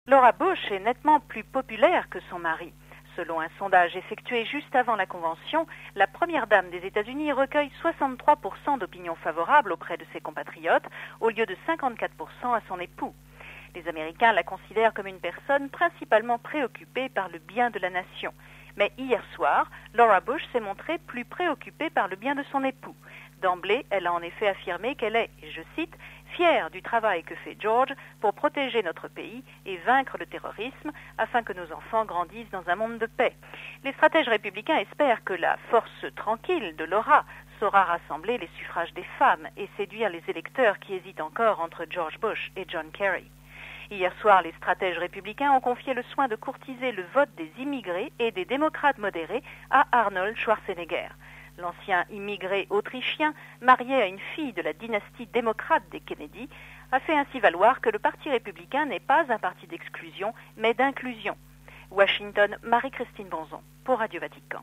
Convention marquée mardi par les vibrants plaidoyers de Laura Bush et Arnold Schwarzenegger. Compte rendu